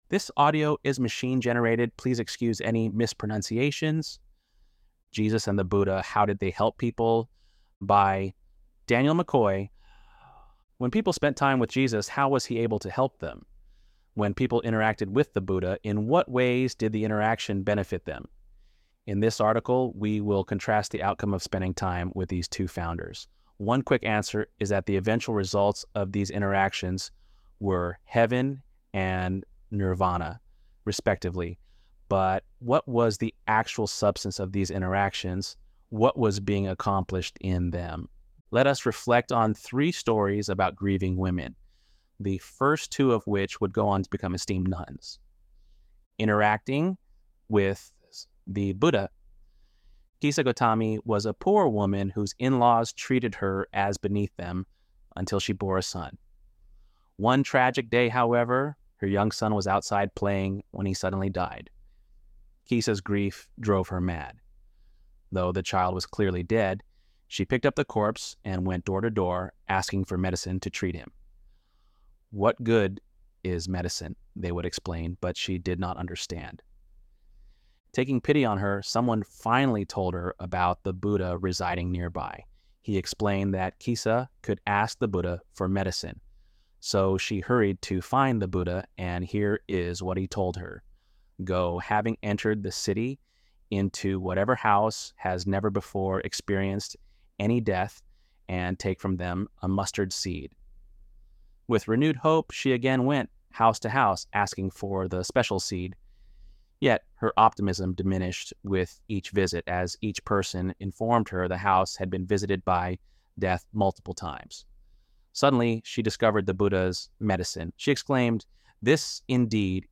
ElevenLabs_7.29_Buddha.mp3